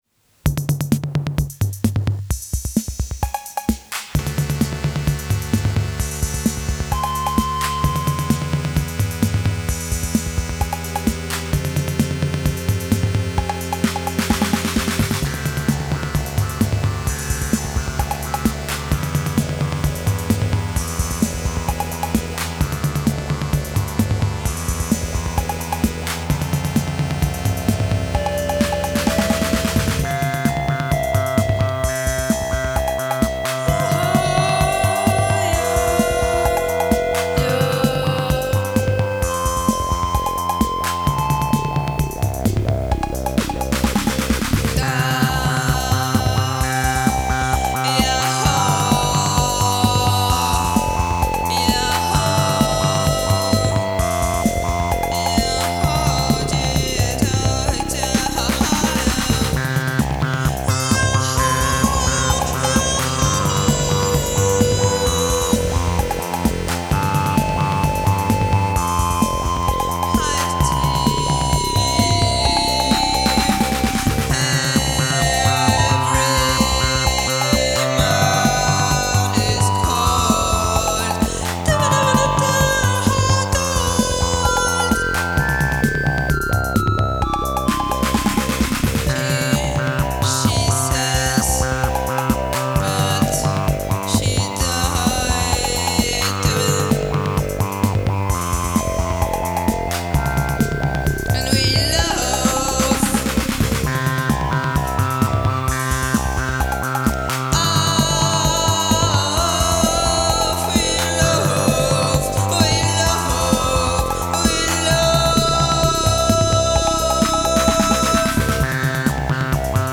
sortis d’une sorte de répétition/improvisation